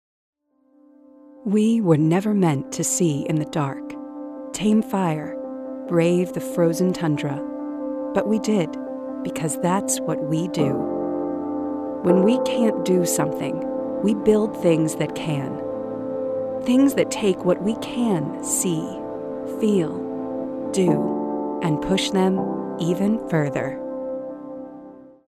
Female Voice Over, Dan Wachs Talent Agency.
Sassy, Dramatic, Conversational.
Inspirational